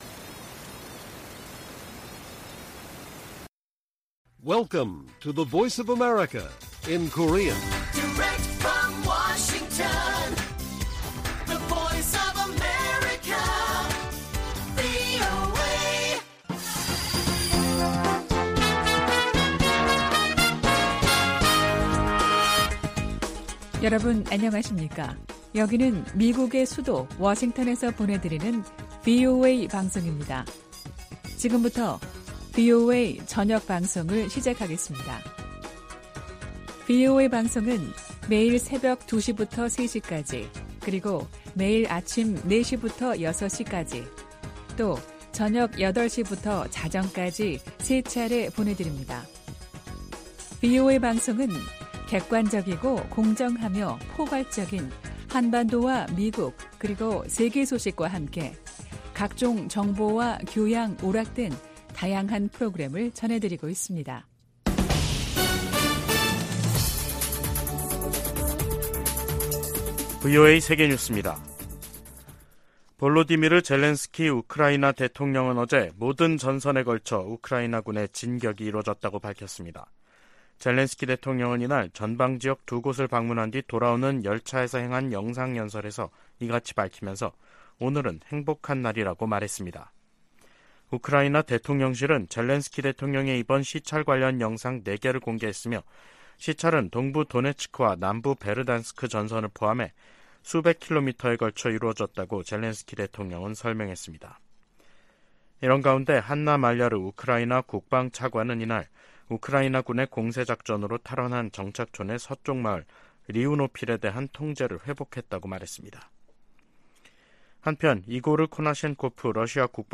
VOA 한국어 간판 뉴스 프로그램 '뉴스 투데이', 2023년 6월 27일 1부 방송입니다. 한반도 긴장 고조 책임이 미국에 있다고 북한이 비난한데 대해 미 국무부는 역내 긴장을 고조시키는 건 북한의 도발이라고 반박했습니다. 미 국무부는 러시아 용병기업 바그너 그룹의 무장 반란 사태가 바그너와 북한 간 관계에 미칠 영향을 판단하기는 이르다고 밝혔습니다. 백악관 인도태평양조정관은 한국 등 동맹과의 긴밀한 관계가 인도태평양 전체의 이익에 부합한다고 말했습니다.